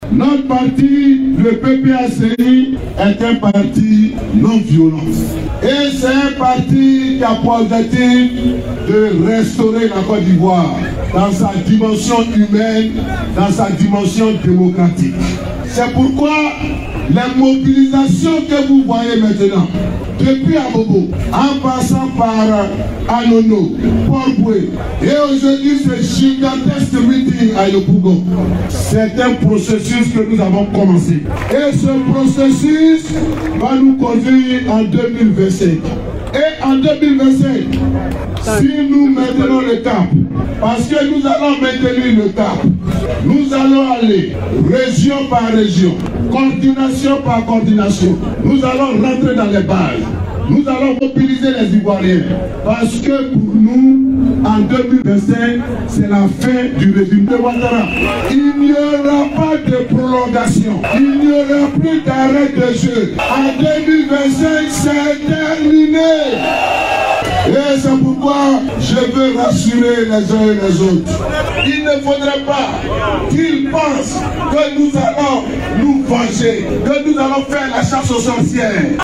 damana-adia-pickass-secretaire-general-du-ppa-ci-meeting-yopougon.mp3